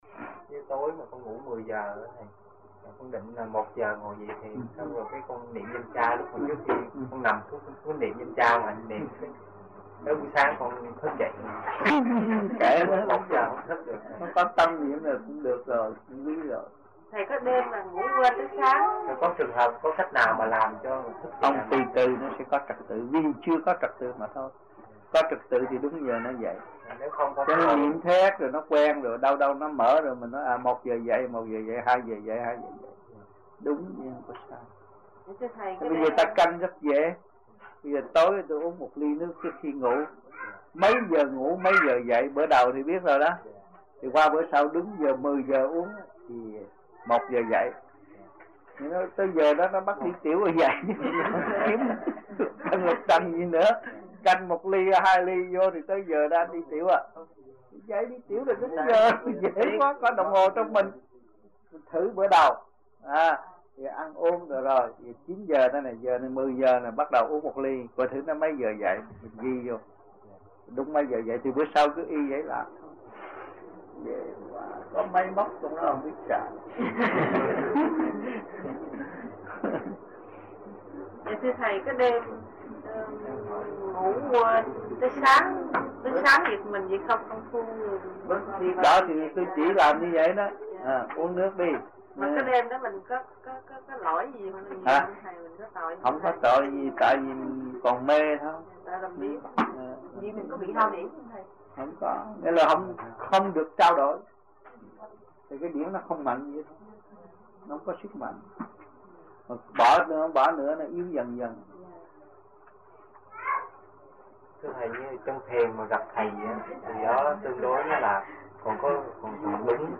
1980-11-20 - AMPHION - THUYẾT PHÁP 10